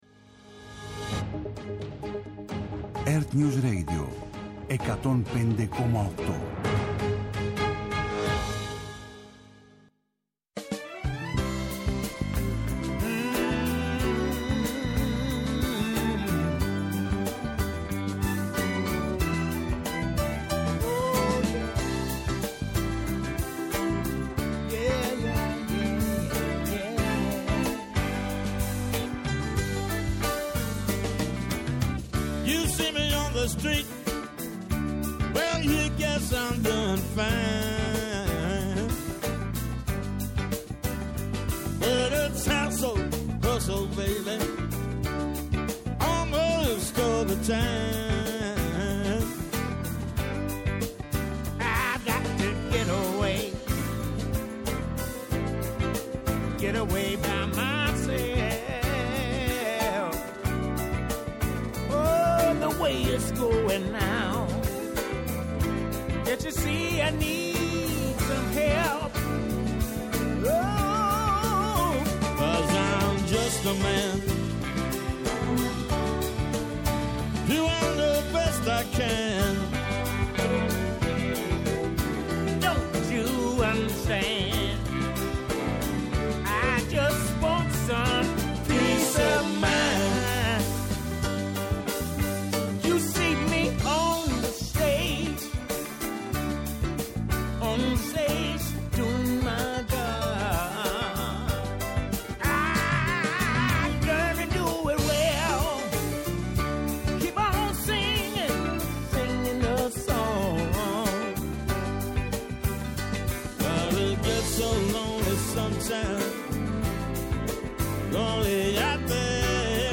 Σε απευθείας σύνδεση με το Μέγαρο Μαξίμου ακούμε τις δηλώσεις του Υπουργού Αγροτικής Ανάπτυξης Κώστα Τσιάρα.